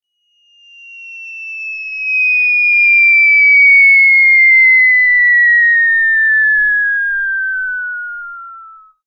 دانلود آهنگ افتادن بمب از افکت صوتی حمل و نقل
جلوه های صوتی
دانلود صدای افتادن بمب از ساعد نیوز با لینک مستقیم و کیفیت بالا